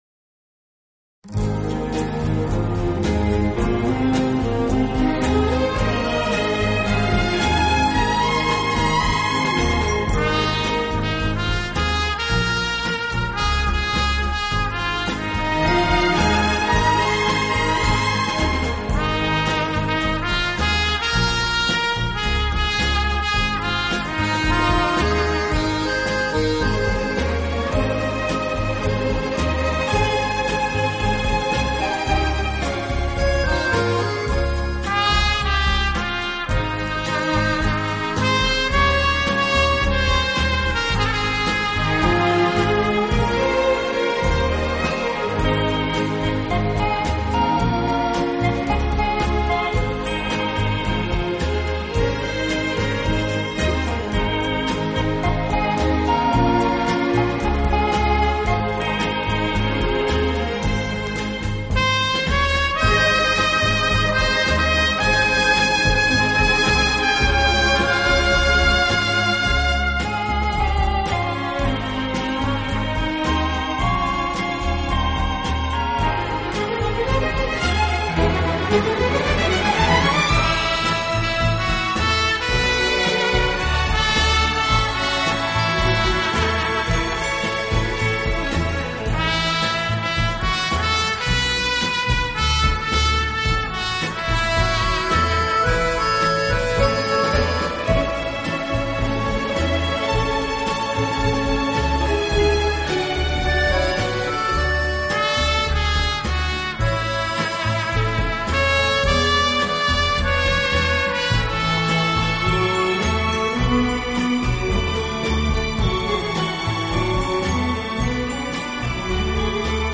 【轻音乐专辑】
以演奏华 丽的管弦乐及轻柔的打击乐著称，往往还加入优美轻柔的合唱而别具风格。